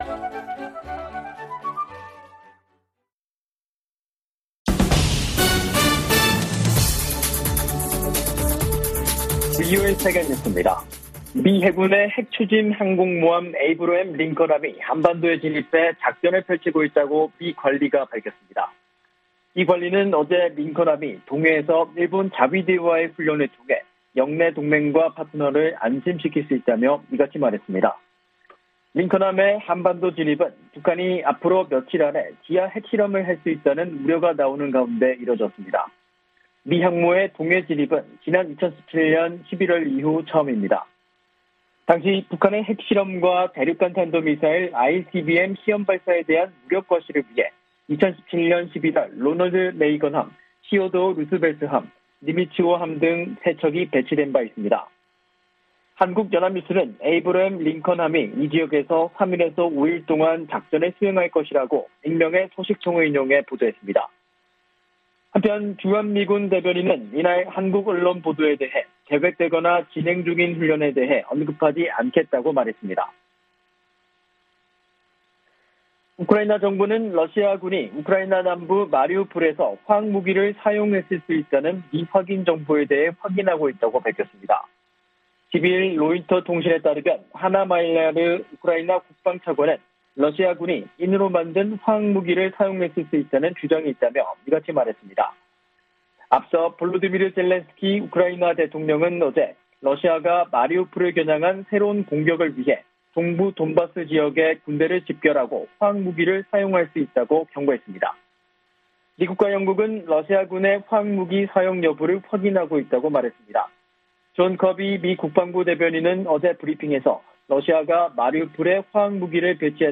VOA 한국어 간판 뉴스 프로그램 '뉴스 투데이', 2022년 4월 12일 3부 방송입니다. 미국의 핵 추진 항공모함 에이브러햄 링컨함이 한반도 동해 공해상에 전개됐습니다.